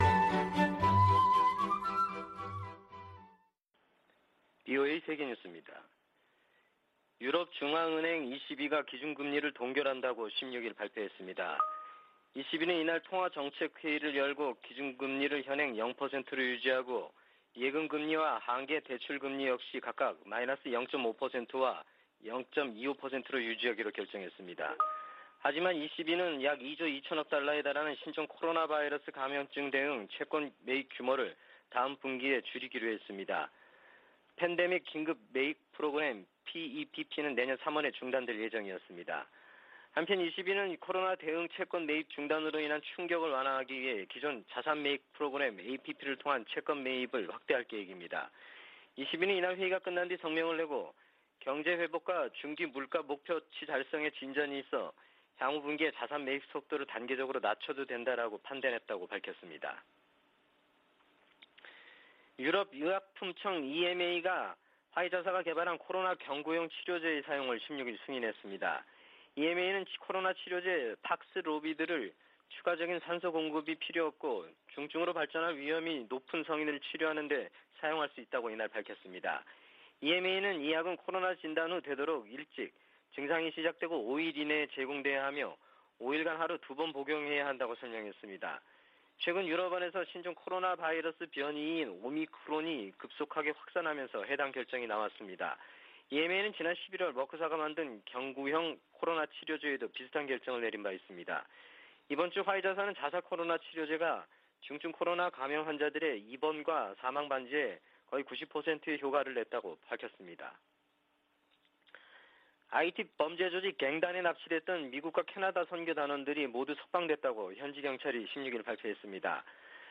VOA 한국어 아침 뉴스 프로그램 '워싱턴 뉴스 광장' 2021년 12월 17일 방송입니다. 유엔 안보리가 올해도 북한 인권 관련 비공개 회의를 개최한 가운데, 일부 이사국들이 북한 정권의 인권유린 실태를 비판했습니다. 미 상원이 국방수권법안을 가결함에 조 바이든 대통령 서명만 거치면 효력을 갖습니다. 신종 코로나바이러스 감염증 사태가 북한에 두고 온 가족들에게 생활비를 보내 온 한국 내 탈북민들의 부담을 높이고 있습니다.